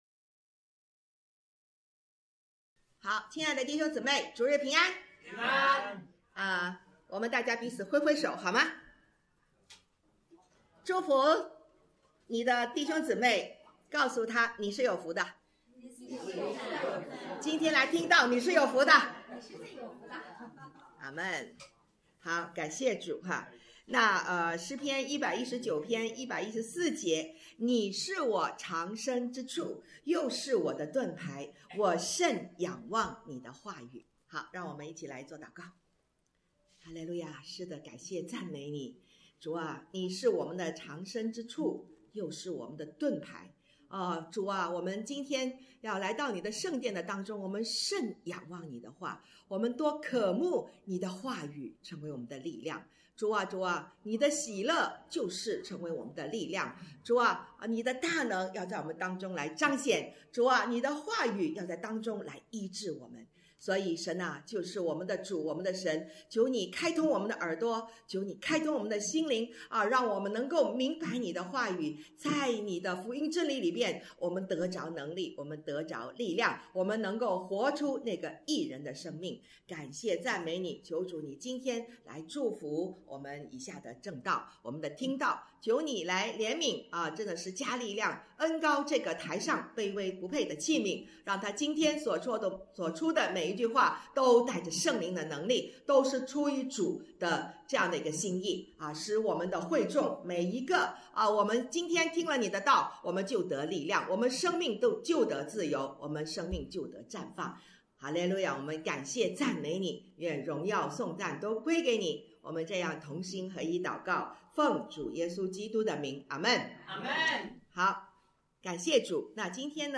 讲道录音 点击音频媒体前面的小三角“►”就可以播放 了 https